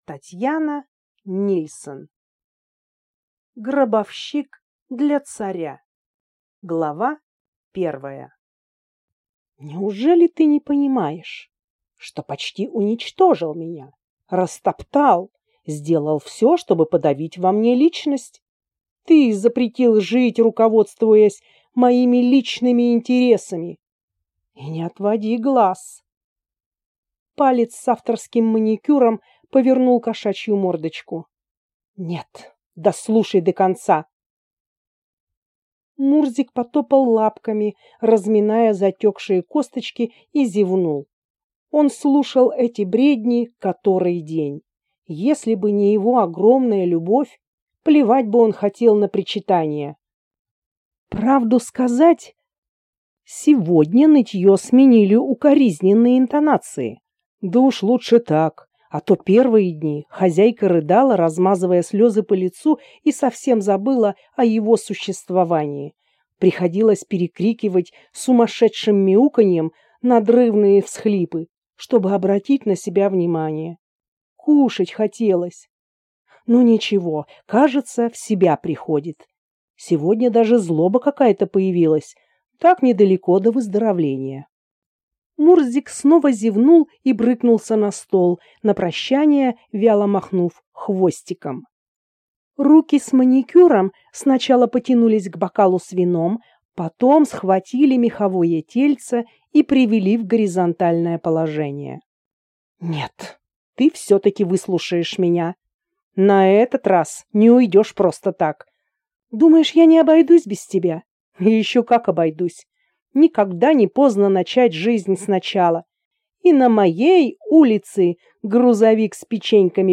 Аудиокнига Гробовщик для царя | Библиотека аудиокниг